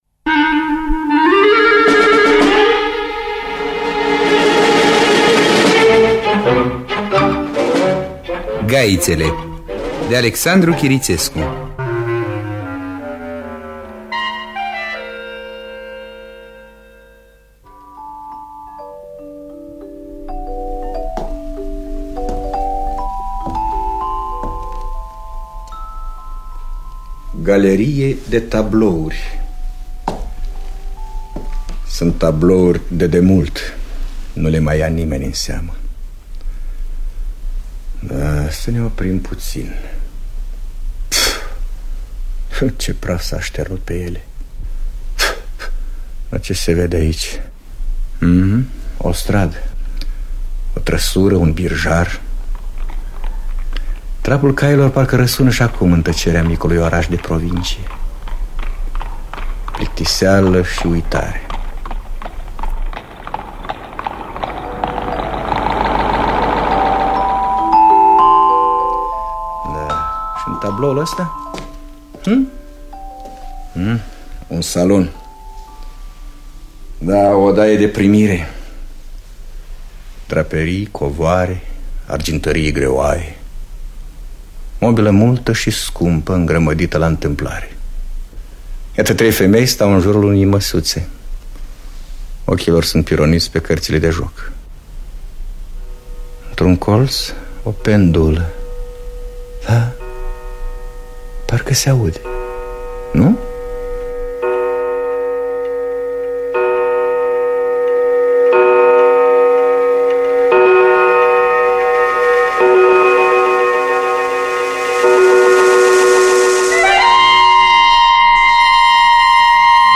Gaiţele de Alexandru Kiriţescu – Teatru Radiofonic Online